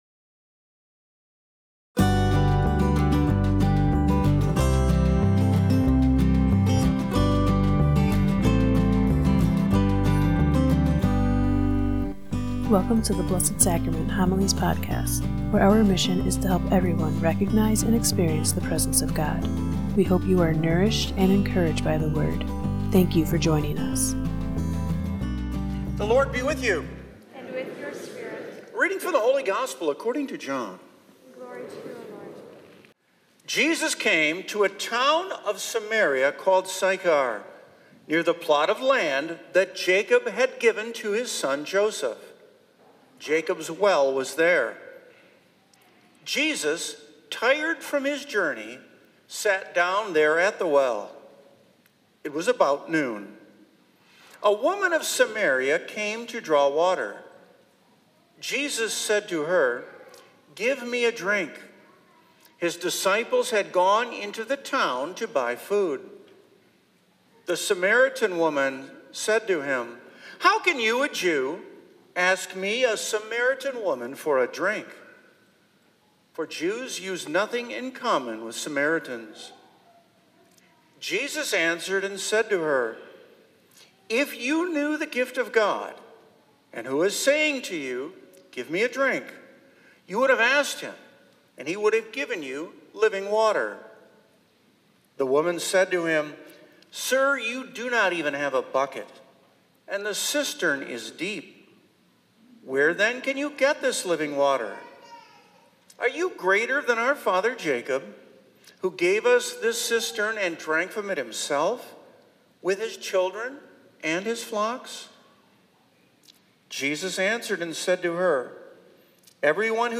March 8, 2026 - Third Sunday of Lent